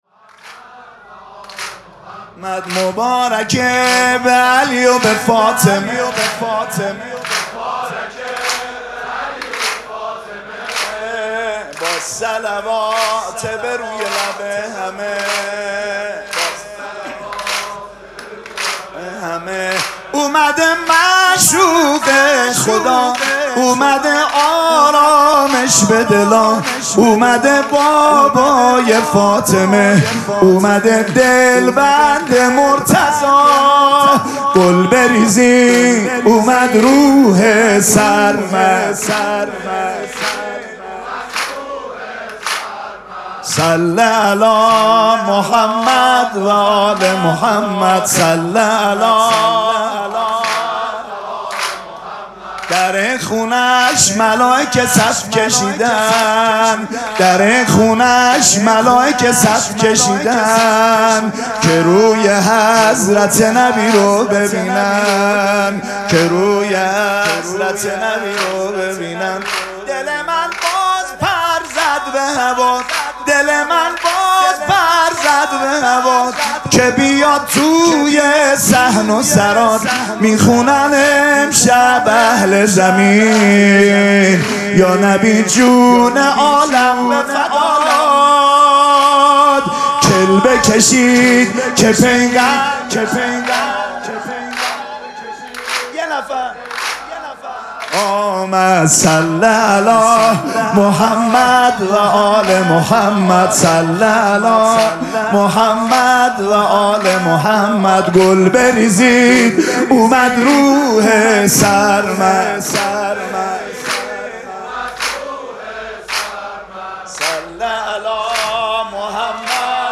جشن ولادت حضرت رسول اکرم و امام صادق علیهماالسلام
حسینیه ریحانه الحسین سلام الله علیها
سرود